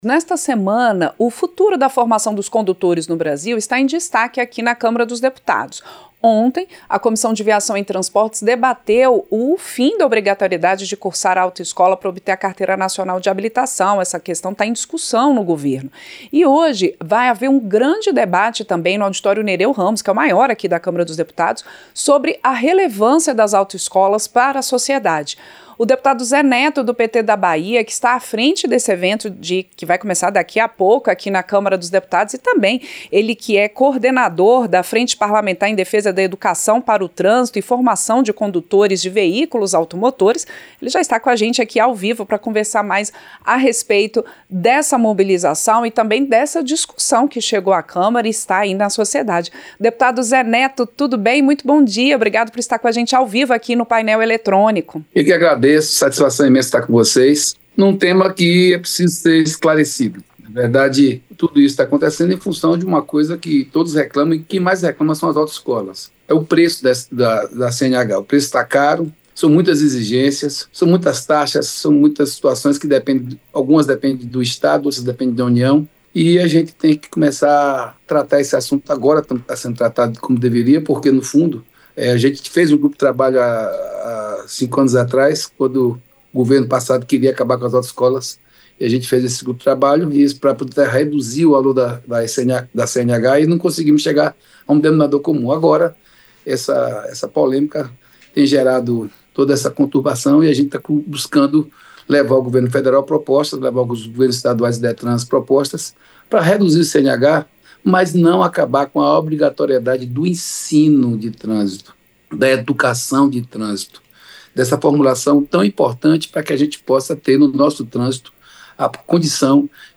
Entrevista - Dep. Zé Neto (PT-BA)